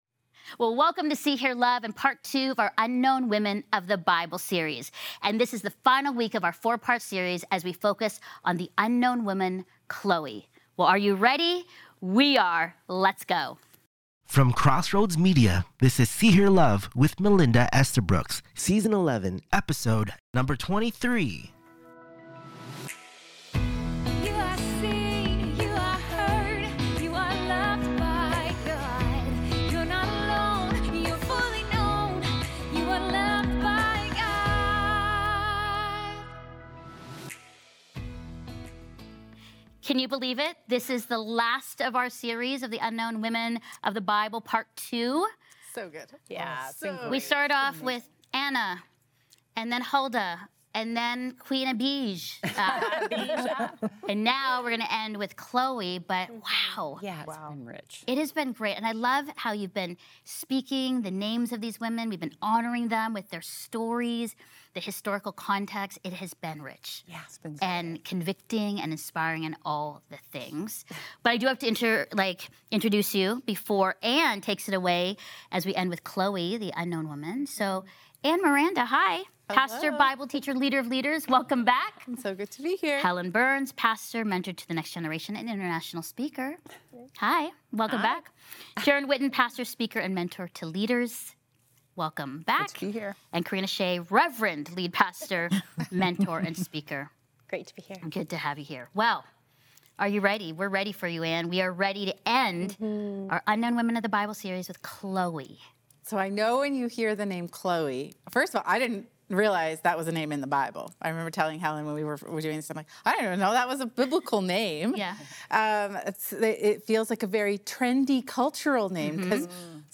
Through her quiet courage and commitment to unity, Chloe models what it means to confront conflict with integrity and become a catalyst for healing in the church. This conversation explores influence without title, courageous truth-telling, and how one obedient life can impact generations.